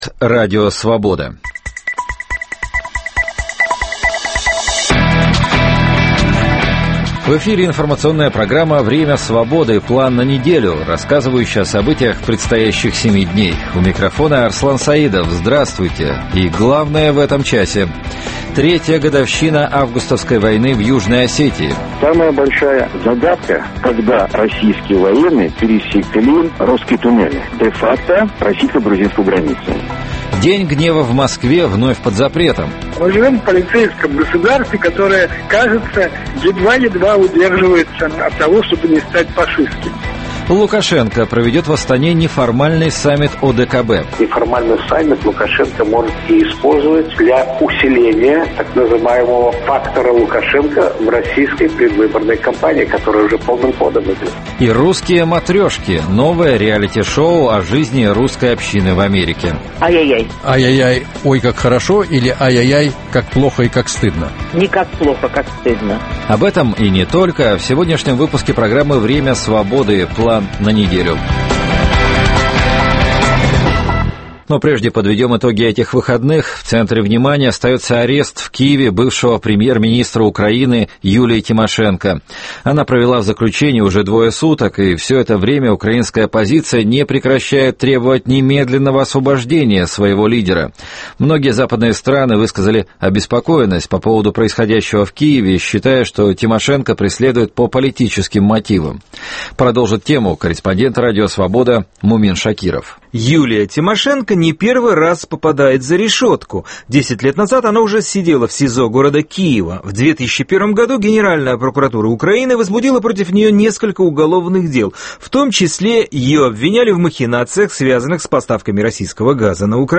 Программу попеременно ведут редакторы информационных программ в Москве и Праге.